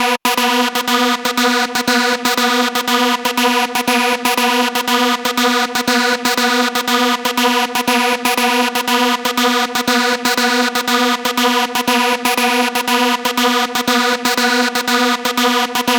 TSNRG2 Lead 027.wav